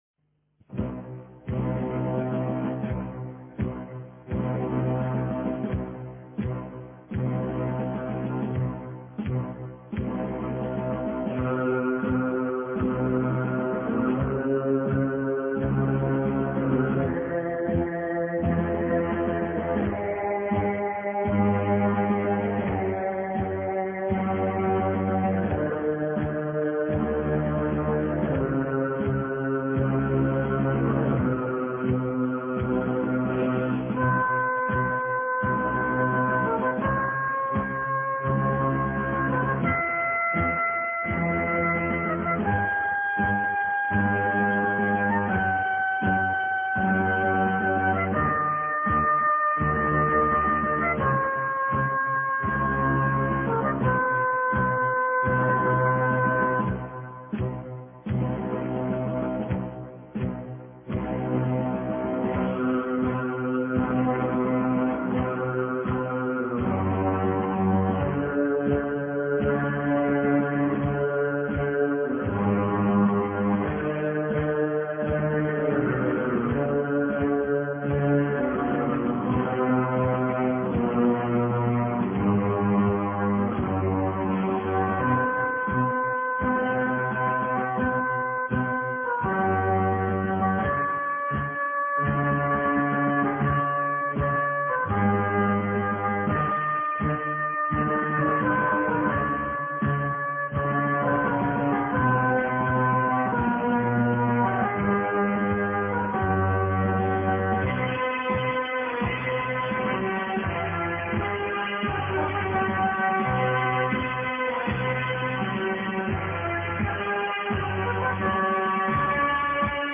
مراثي الامام الخميني (ره)